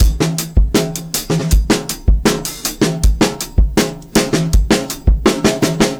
Session_Drum_Break_80bpm.wav